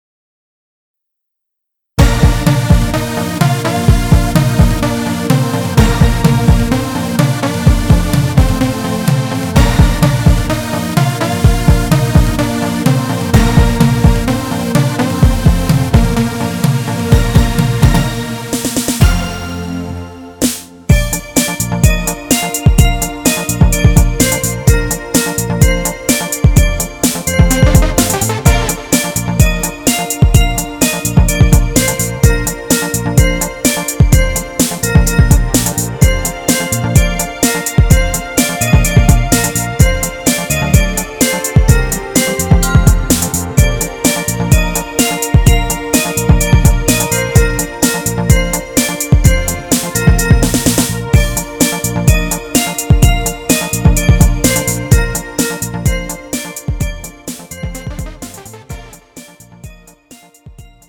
음정 Bb 키
장르 가요 구분 Pro MR